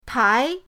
tai2.mp3